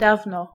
d d
dawno door